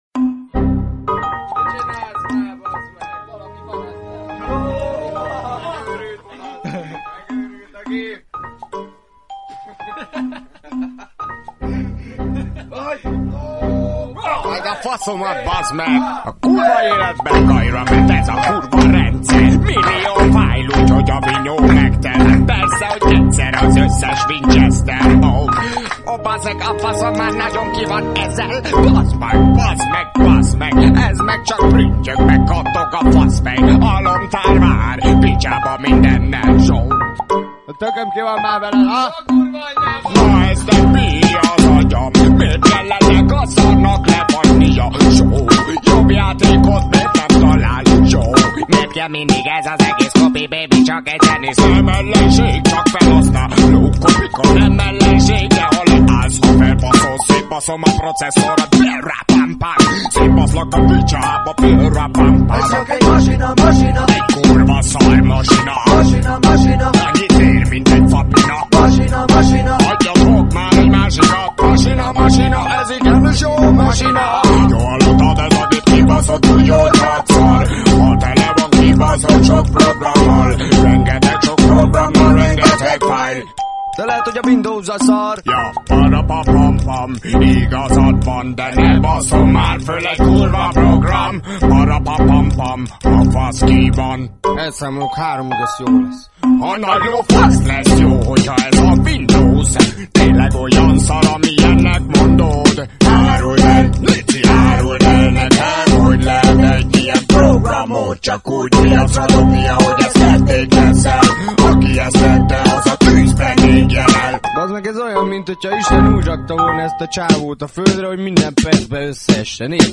CopyCon Rap (Hungarian)